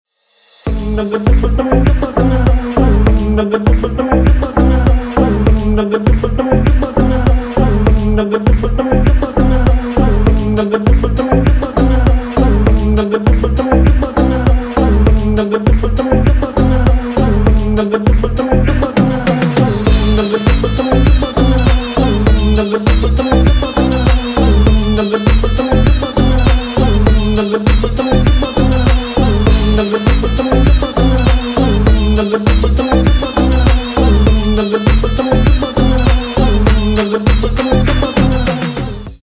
восточные мотивы
Ремикс с восточными мотивами